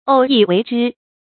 偶一为之 ǒu yī wéi zhī
偶一为之发音
成语正音为，不能读作“wèi”。